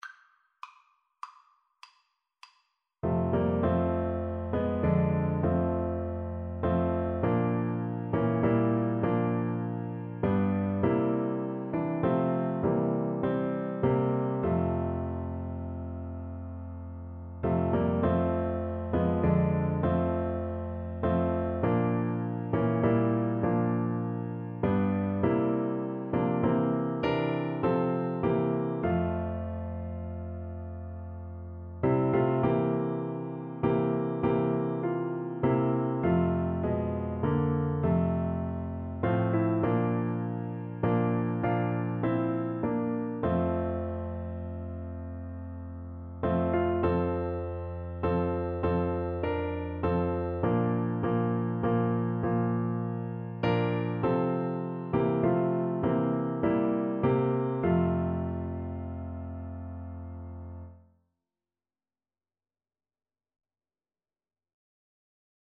F major (Sounding Pitch) (View more F major Music for Oboe )
6/8 (View more 6/8 Music)